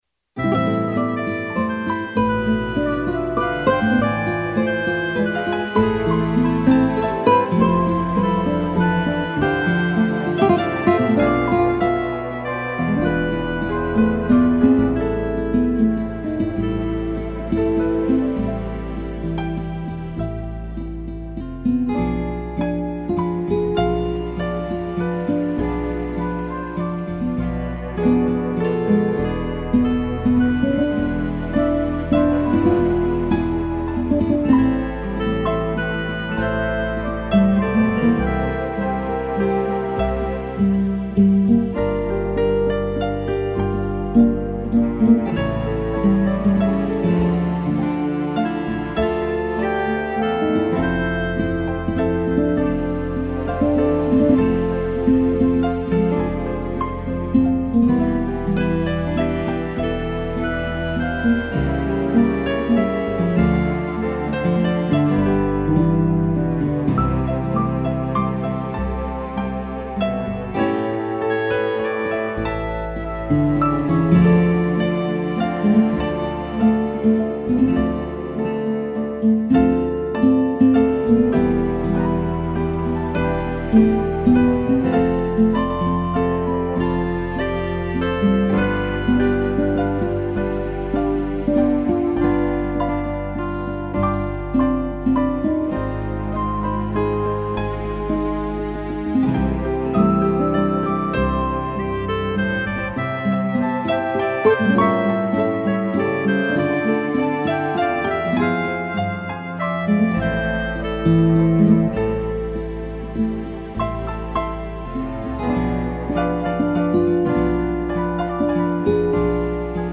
piano, flauta e clarineta
(instrumental)